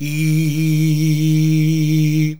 IIIIIIH A#.wav